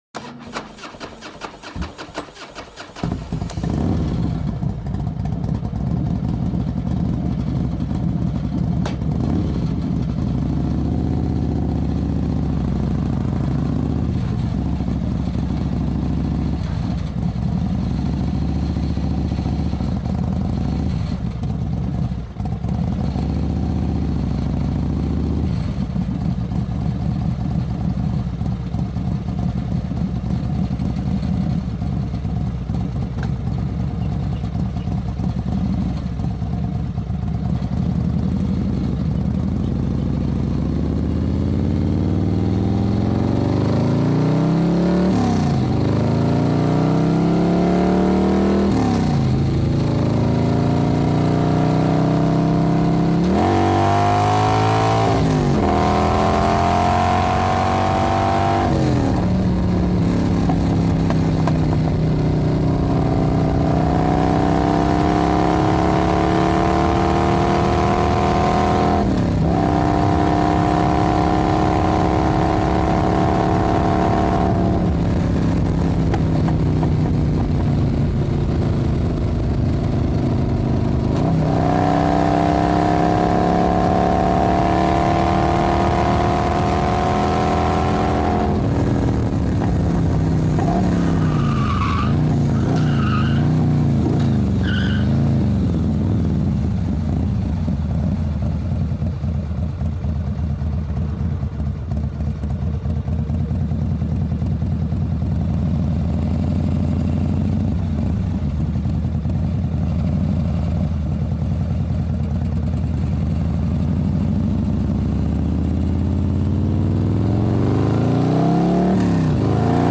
دانلود آهنگ موتور 3 از افکت صوتی حمل و نقل
جلوه های صوتی
دانلود صدای موتور 3 از ساعد نیوز با لینک مستقیم و کیفیت بالا